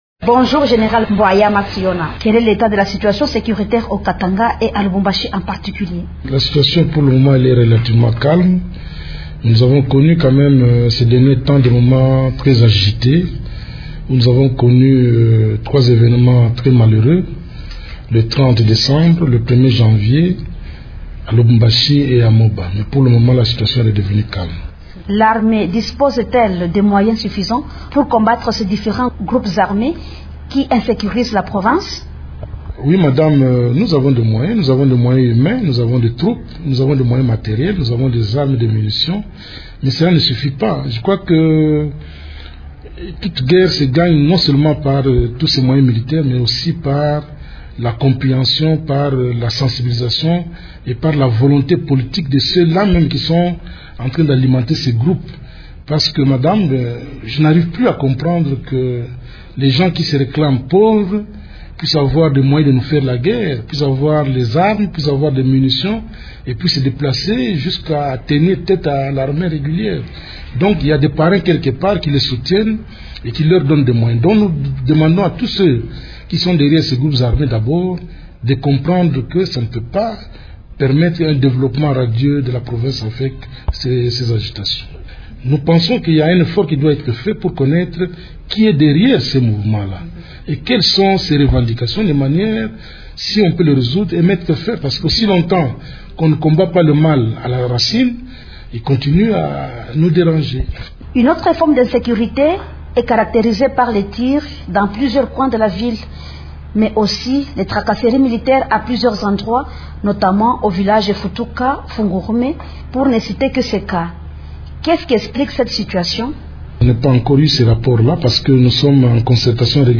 Le commandant de la 6e région militaire au Katanga, général Rombaut Mbwayama Nsiona, est l’invité de Radio Okapi ce vendredi 10 janvier.